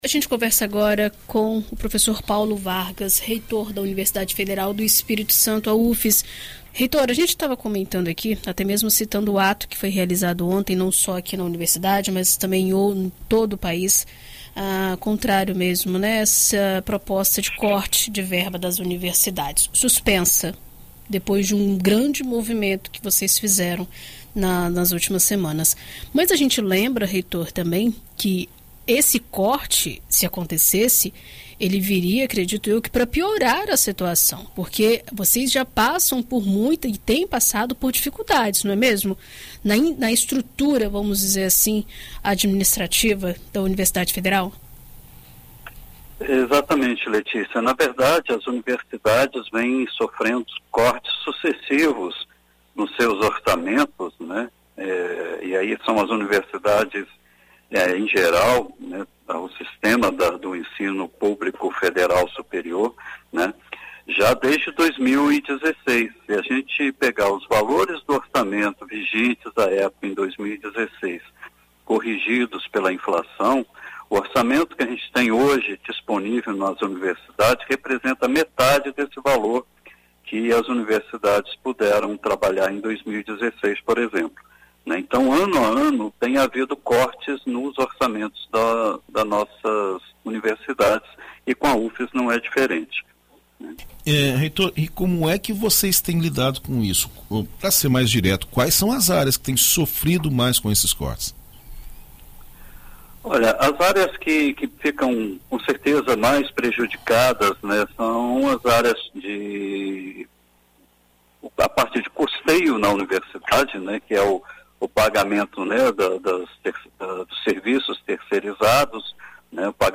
Em entrevista à BandNews FM Espírito Santo nesta quarta-feira (19), o reitor da Universidade Federal do Espírito Santo (Ufes), Paulo Vargas, detalha a situação vivenciada na instituição capixaba e como a universidade tem lidado com o déficit.